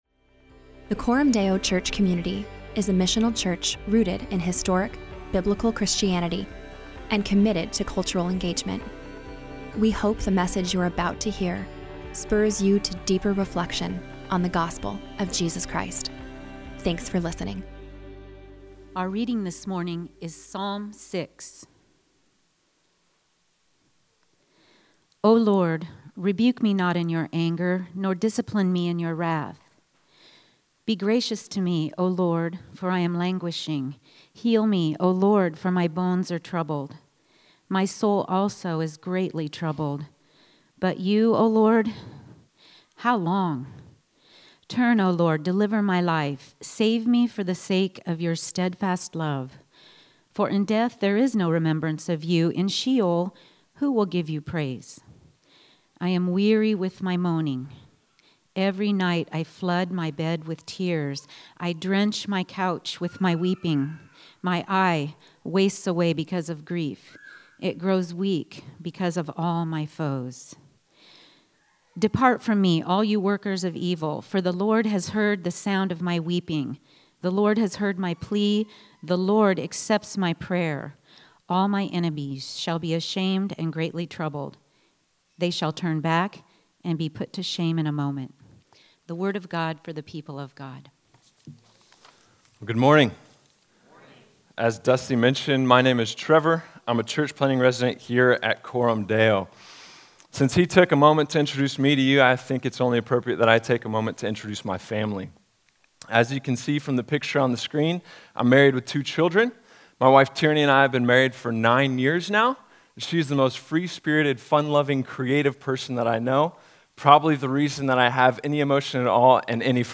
So every summer, the people of Coram Deo dwell in the Psalms - the worship songbook of God’s people - to learn the language and practices of Christian worship. This preaching series is a progressive journey